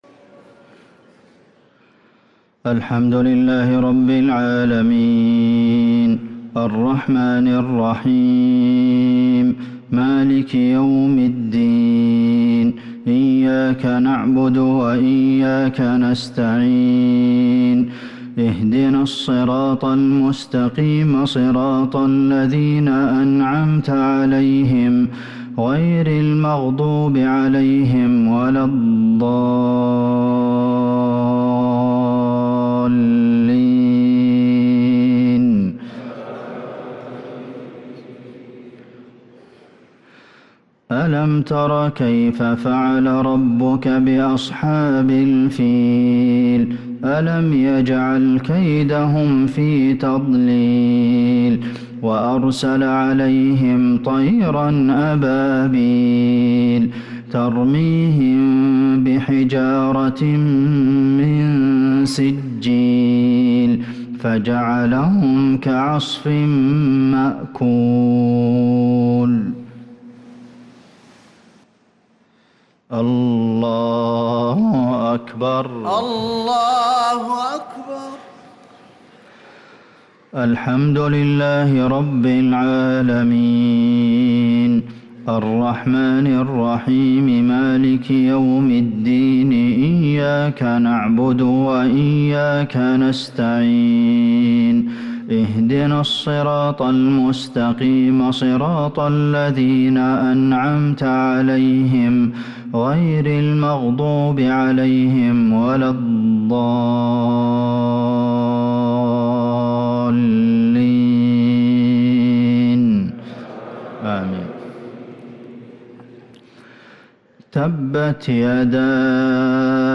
صلاة المغرب للقارئ عبدالمحسن القاسم 27 جمادي الآخر 1444 هـ
تِلَاوَات الْحَرَمَيْن .